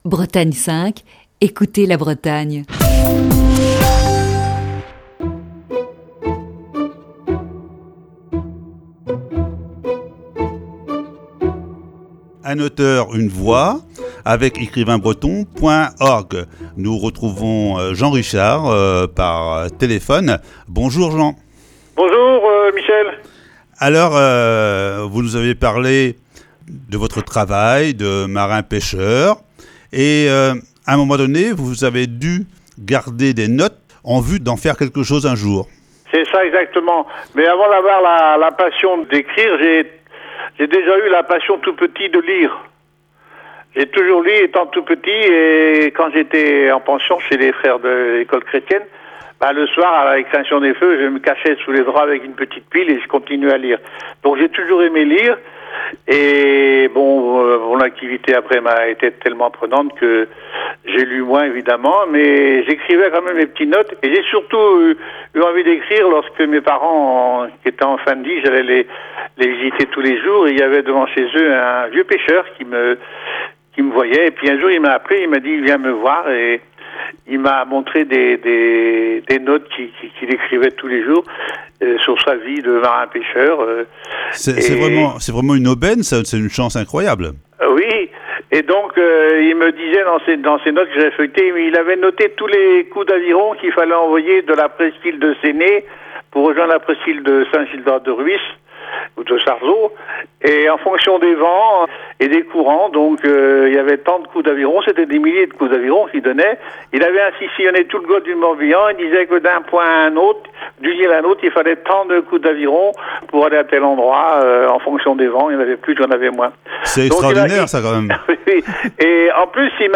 Voici ce mercredi, la troisième partie de cette série d'entretiens.